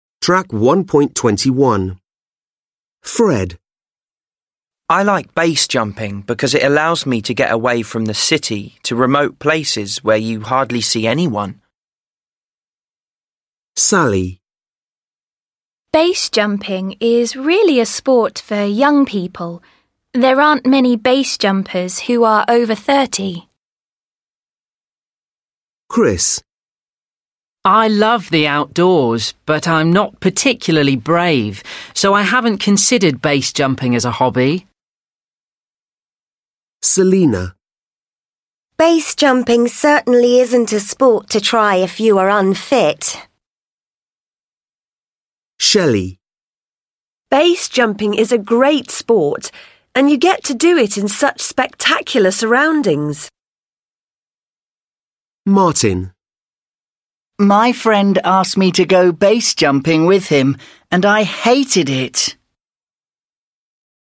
Then listen to six people talking about BASE jumping.